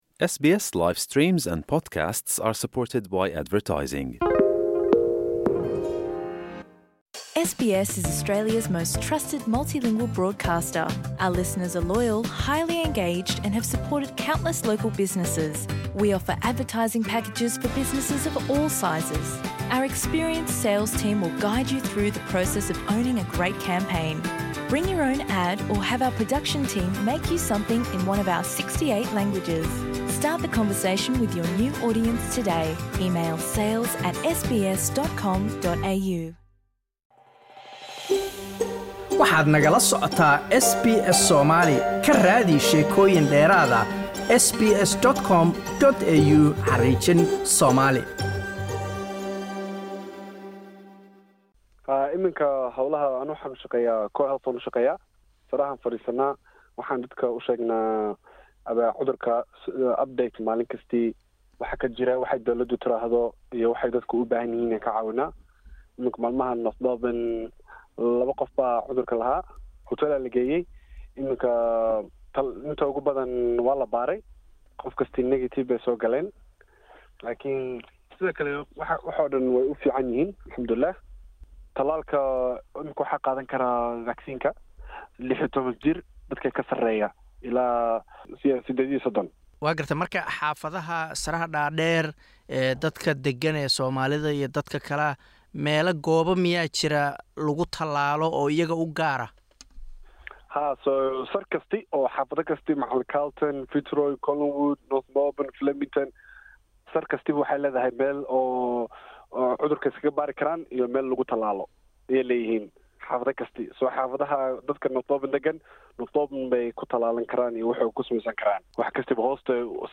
Waxaan ka waraysay